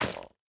Minecraft Version Minecraft Version 1.21.5 Latest Release | Latest Snapshot 1.21.5 / assets / minecraft / sounds / block / roots / break4.ogg Compare With Compare With Latest Release | Latest Snapshot
break4.ogg